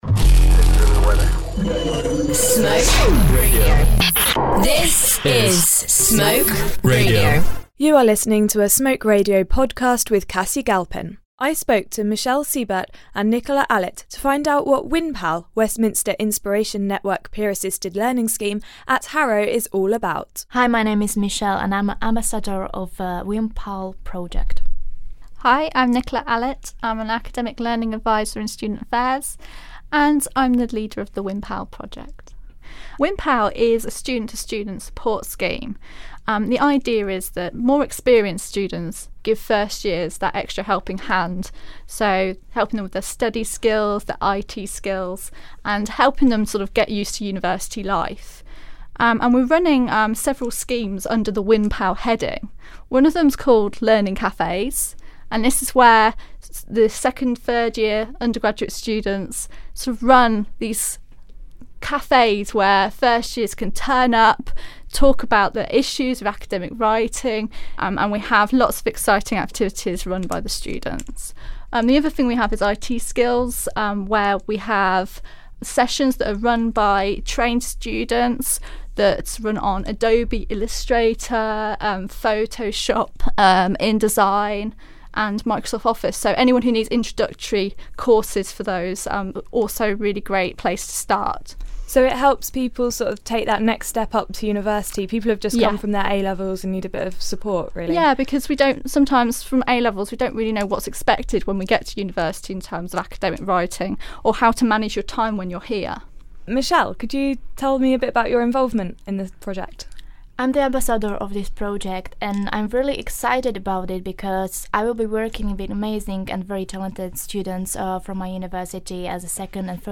There are opportunities for Second and Third year students to volunteer with WIN PAL, to find out more listen to the interview.